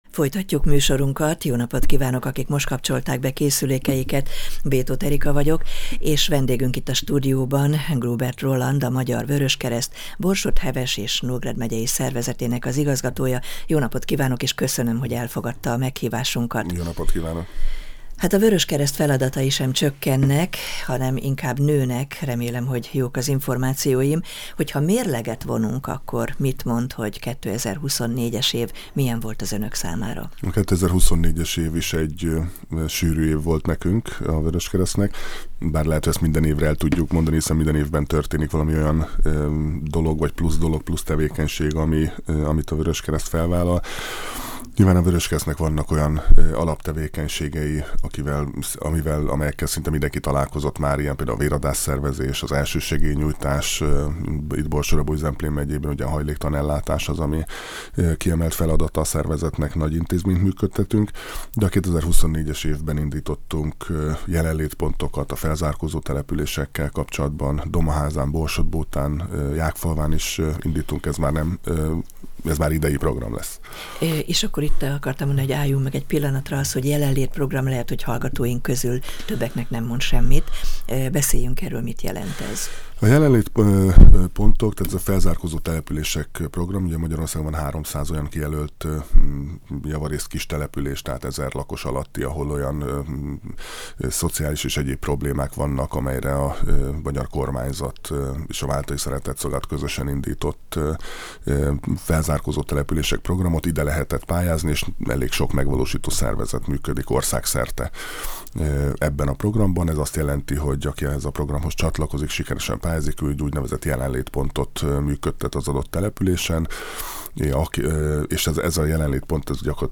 volt a Csillagpont Rádió vendége. Beszélt a 2025.-ös célokról, a múlt év eredményeiről, a hajléktalan ellátás nehézségeiről, és a véradás szervezésről.